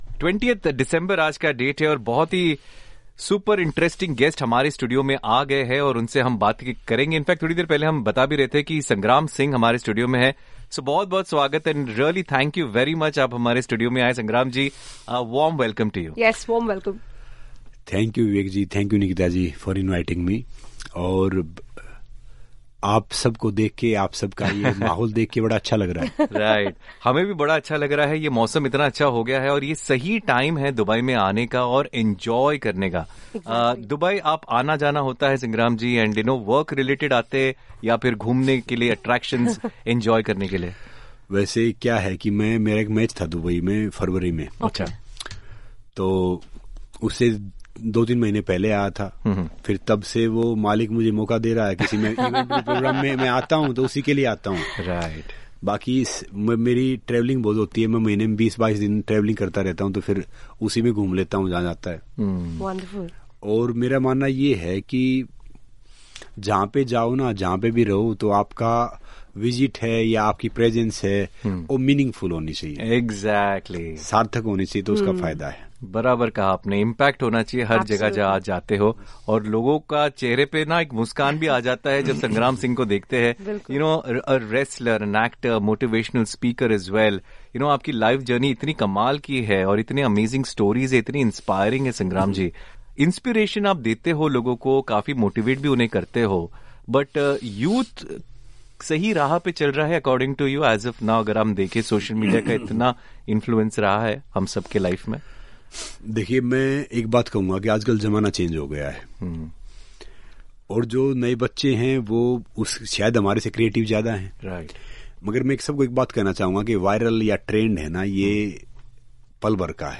Wrestler, Actor, Motivational Speaker Sangram Singh was in our studios where we spoke about food, health, wrestling and much more.